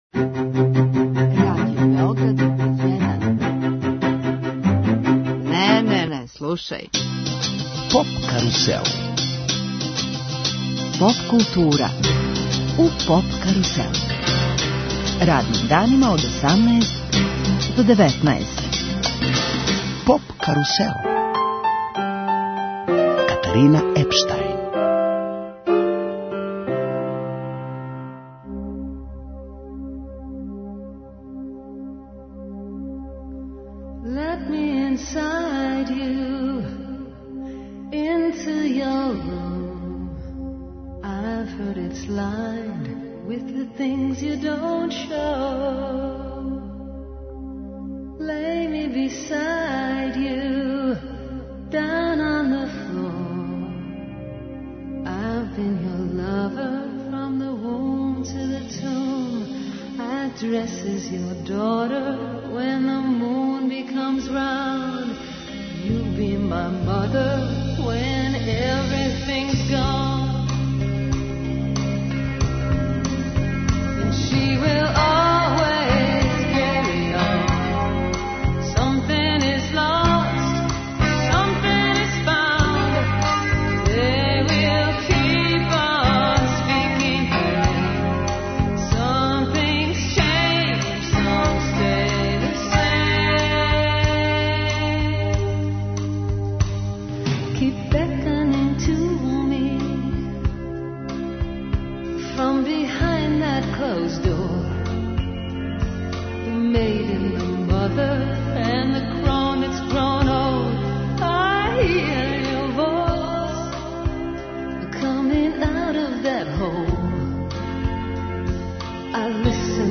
Уживо у емисији наступиће дечји хор Врапчићи.